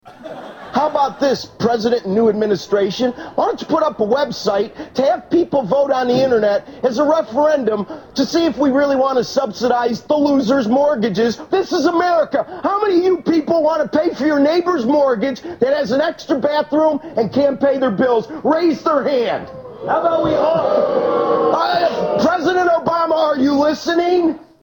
Tags: Rick Santelli Jon Stewart Jim Cramer Rants about the US economy Economic rants